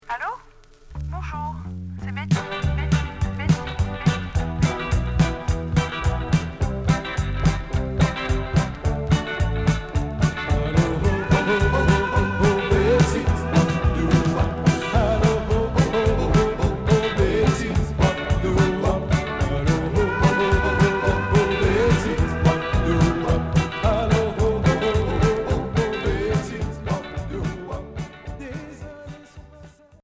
Rockabilly 60's Premier 45t retour à l'accueil